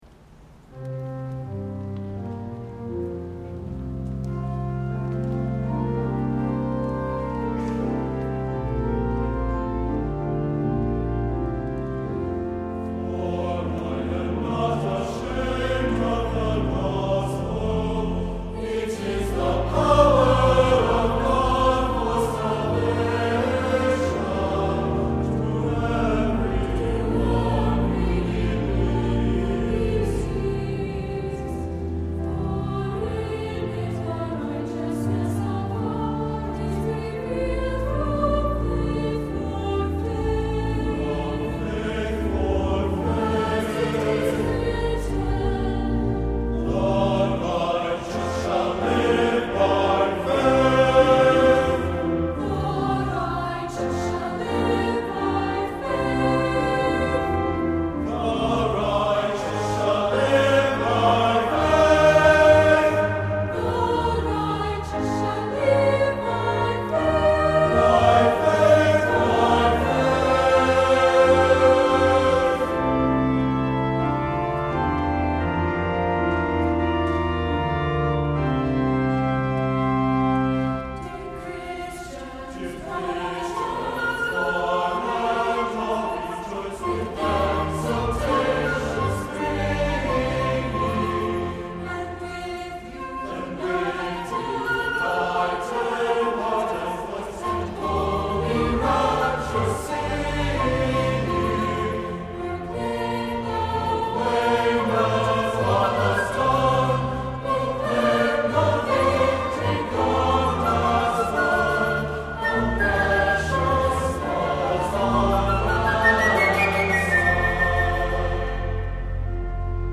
SATB, Flute (or Oboe), and Organ